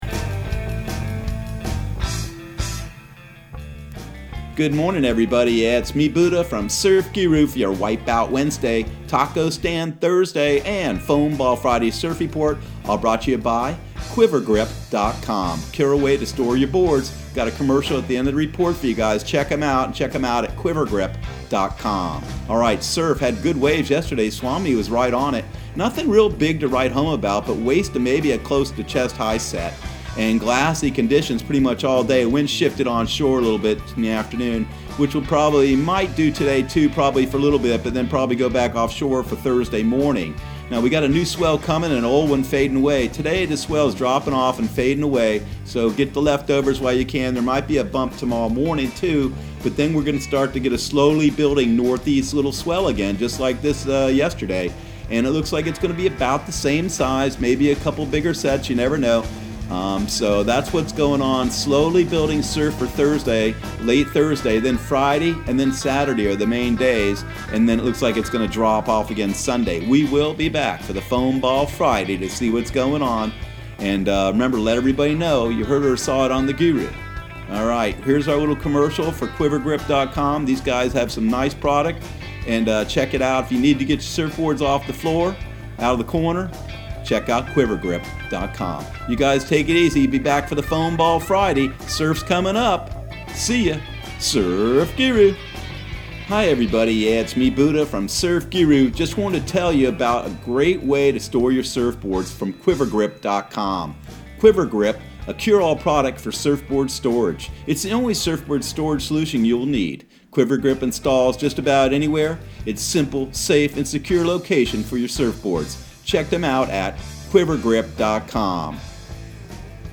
Surf Guru Surf Report and Forecast 03/25/2020 Audio surf report and surf forecast on March 25 for Central Florida and the Southeast.